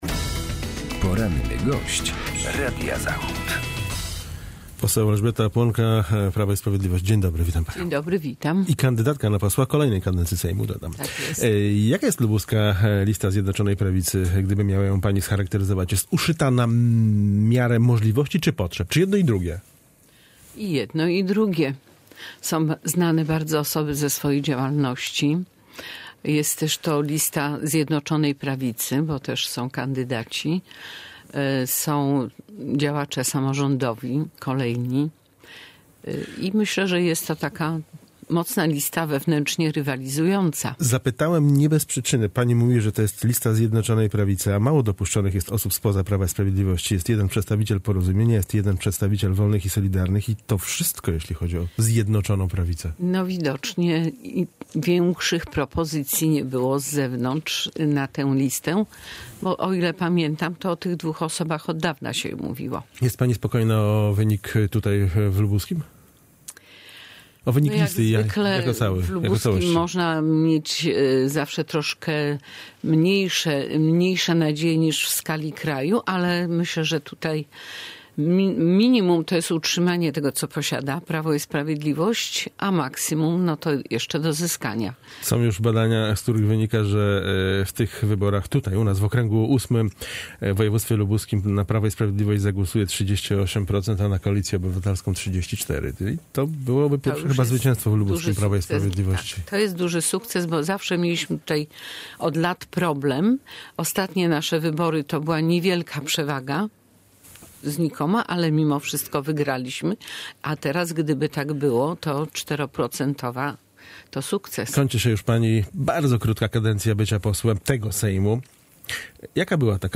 Z posłanką PIS rozmawia